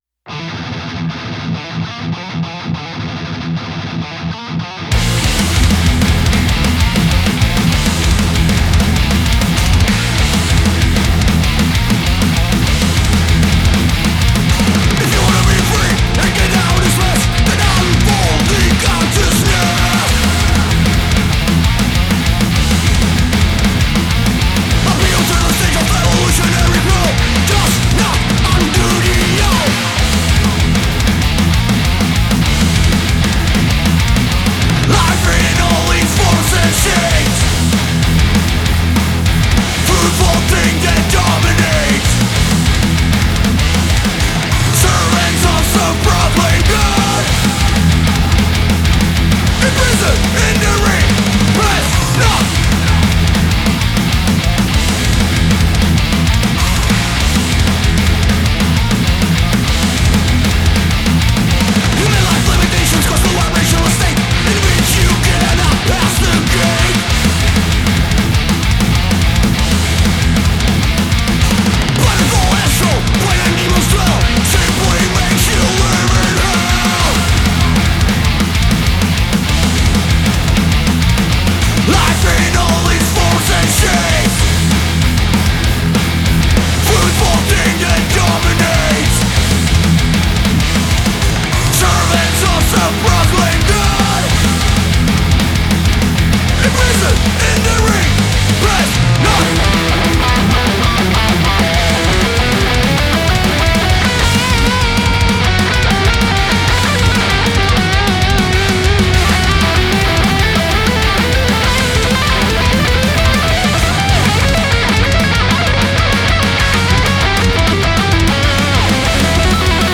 thrash metal band z Trutnova.